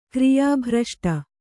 ♪ kriyā bhraṣṭa